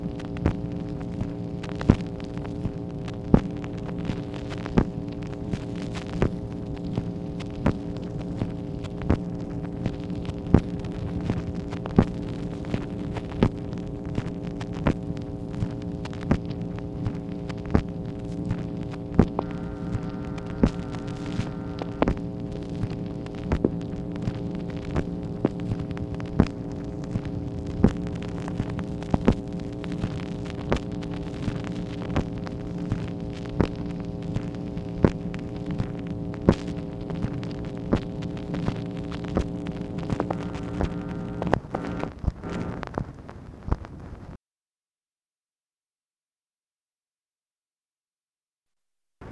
MACHINE NOISE, August 13, 1964
Secret White House Tapes | Lyndon B. Johnson Presidency